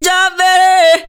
VOC 05.AIF.wav